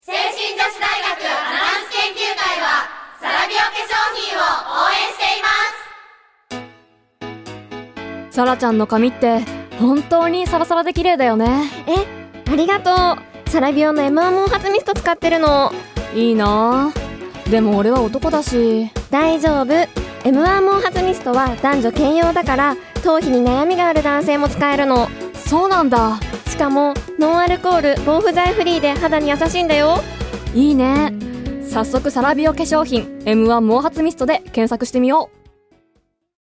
どれも清々しいお声で、皆さんの笑顔が浮かぶステキなＣＭです！
聖心女子大学アナウンス研究会による「ラジオCM」